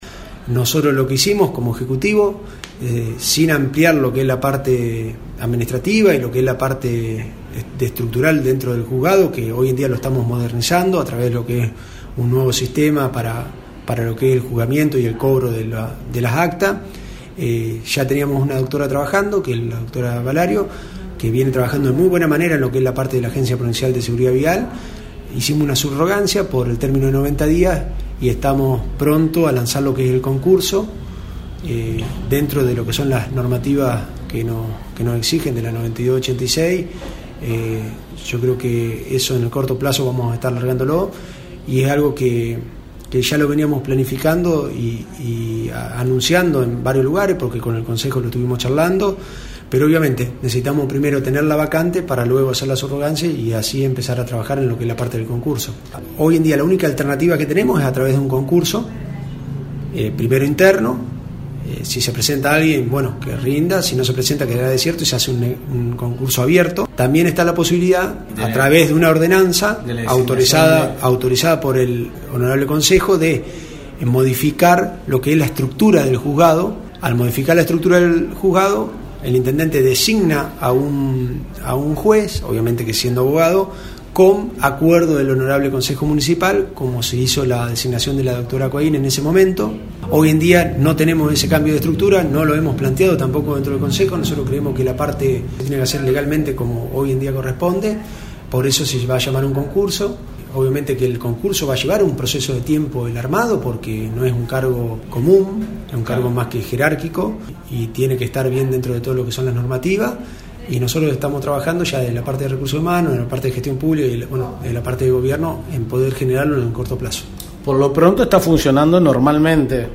Lo explica el Secretario de Gobierno, Diego Palmier.